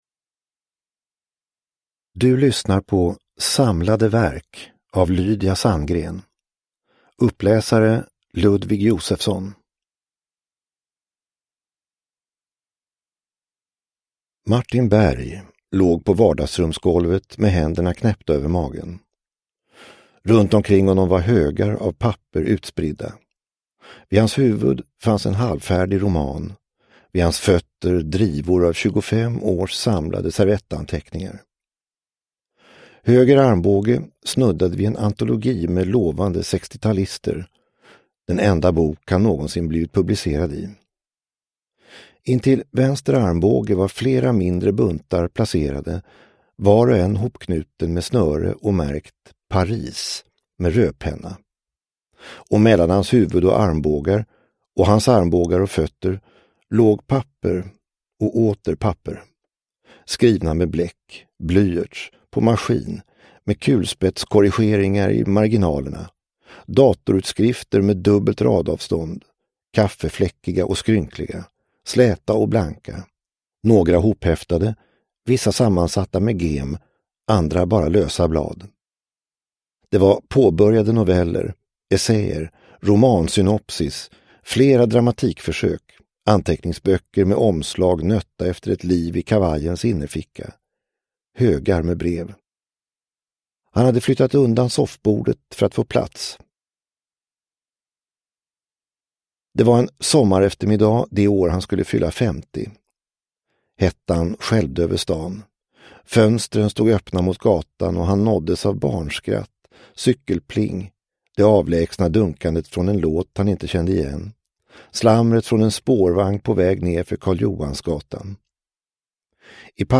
Samlade verk / Ljudbok